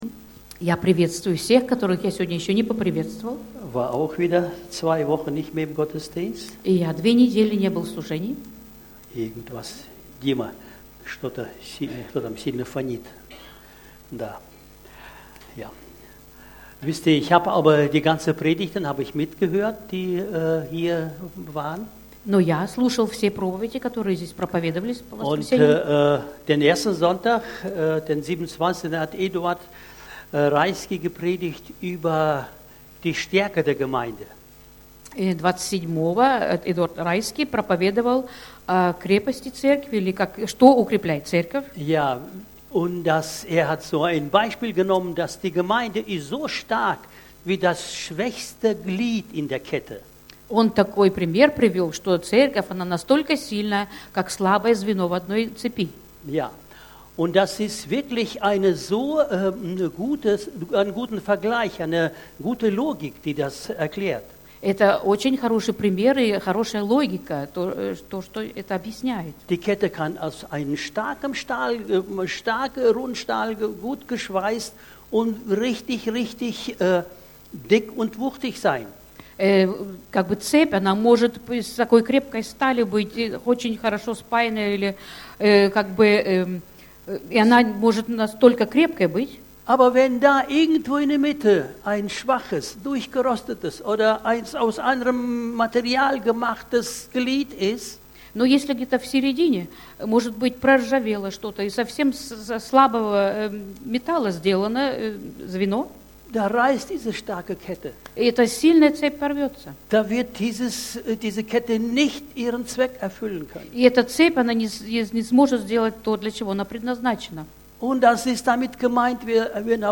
Predigten – Freie Evangeliums Christengemeinde Löningen